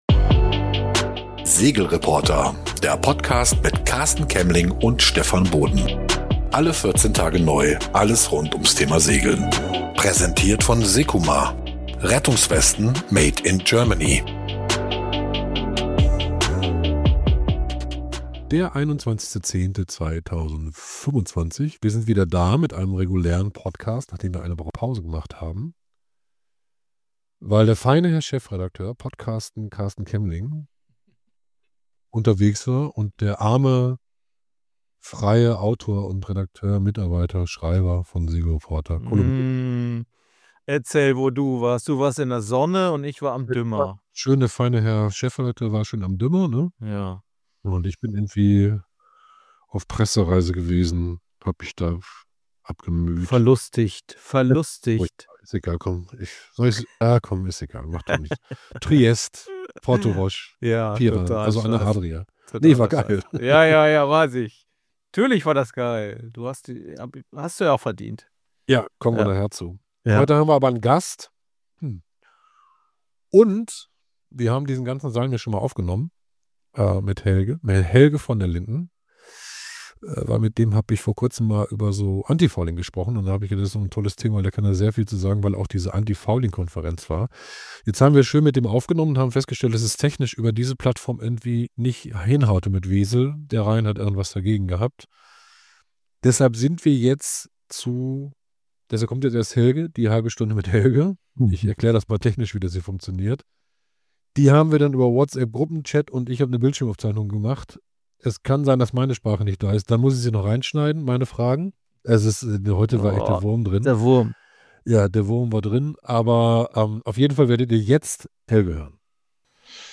ein interessantes Gespräch